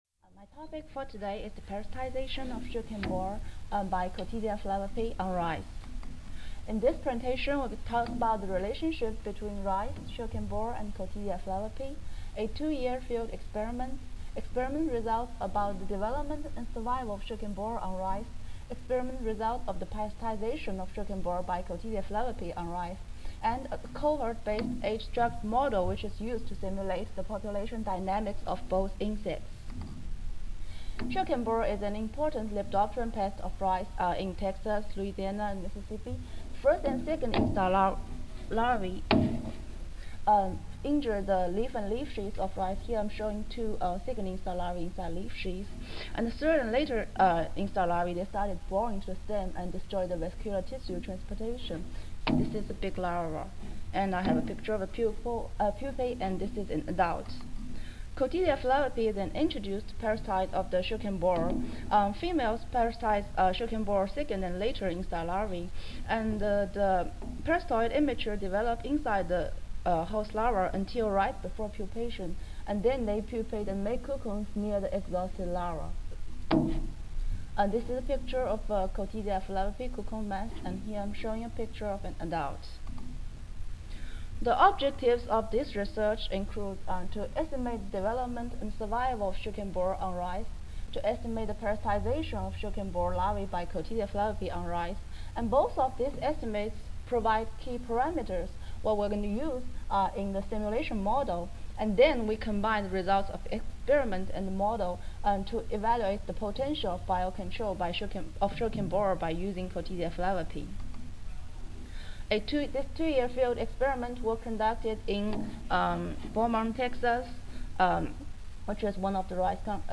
8:17 AM Recorded presentation Audio File 0331 Parasitization of sugarcane borer (Lepidoptera: Crambidae) by Cotesia flavipes (Hymenoptera: Braconidae) on rice